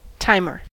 timer: Wikimedia Commons US English Pronunciations
En-us-timer.WAV